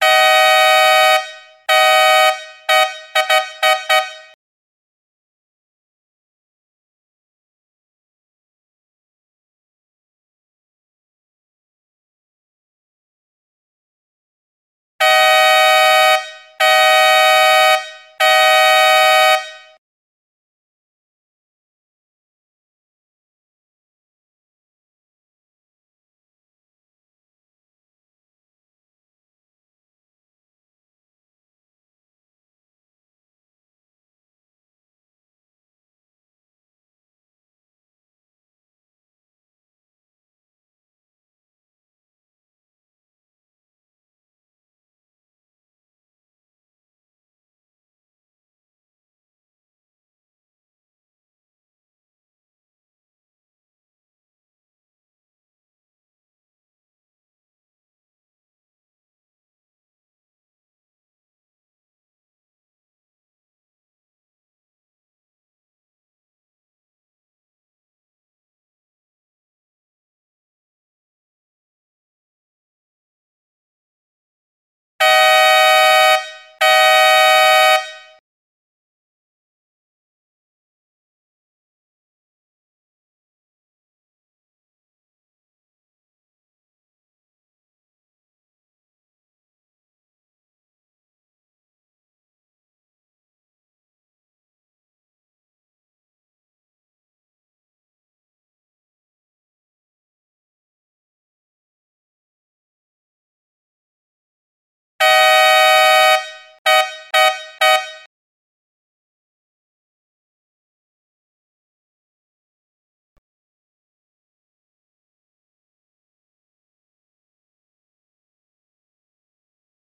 There will be an attention signal ( Several mixed length blasts) - approx 15 seconds before the warning. The start of each sound signal indicates the proper time in the sequence.
The Race leader may use a recording of the sound signals for the starting sequence - the sound file is available by
DSA 3 minute Starting Sequence (Truck Horn).mp3